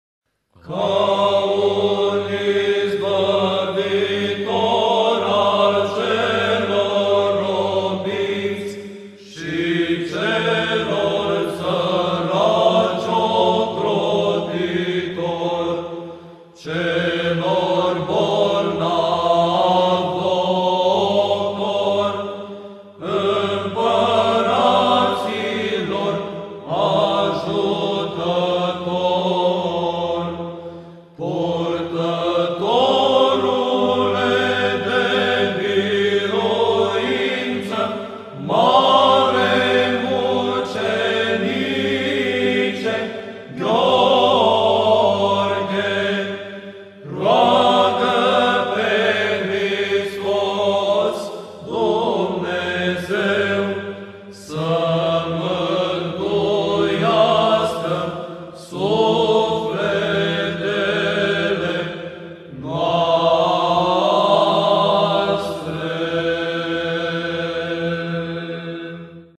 Troparul-Sfantului-Mucenic-Gheorghe.mp3